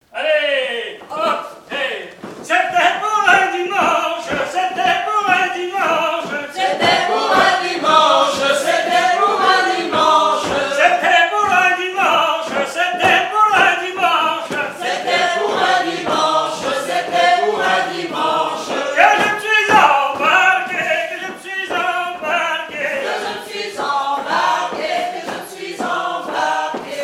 danse : ronde : grand'danse
7e festival du chant traditionnel : Collectif-veillée
Pièce musicale inédite